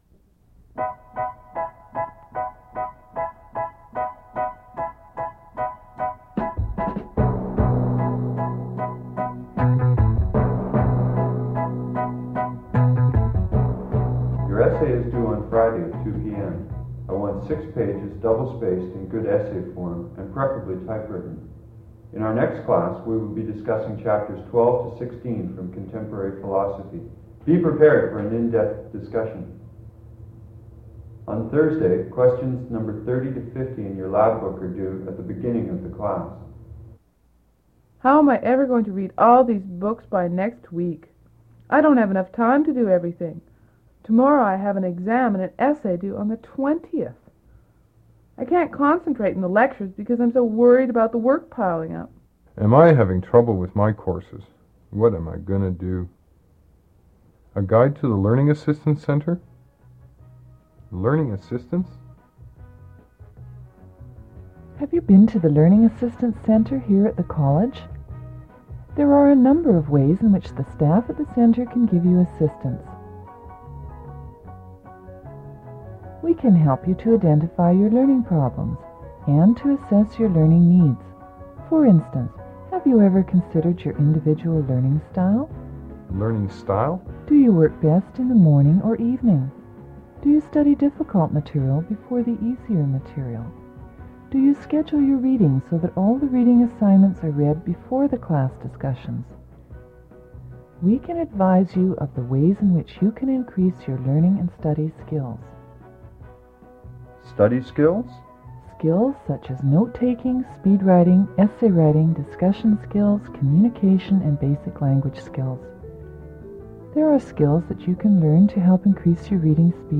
Audio non-musical
Voiceover narrative with background music, describing the services and benefits of the Learning Assistance Centre.
audio cassette